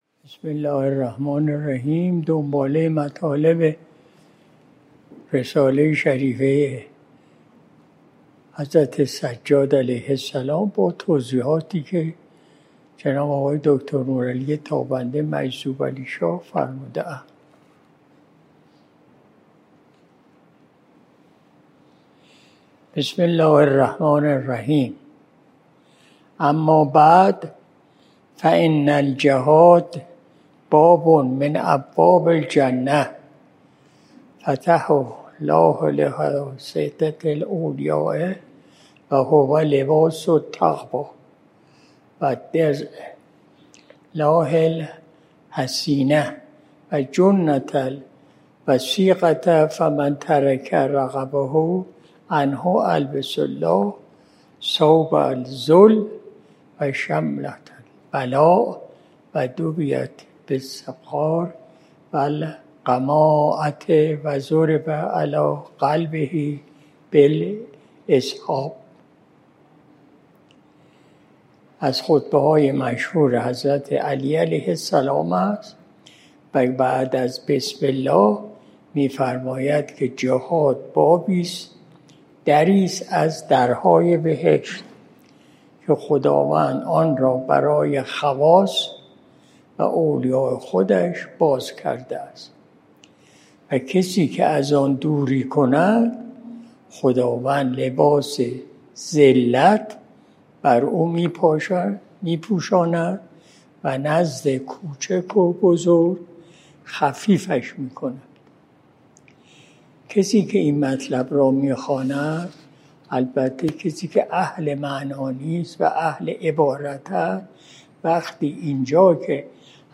مجلس شب جمعه ۲۶ مرداد ماه ۱۴۰۲ شمسی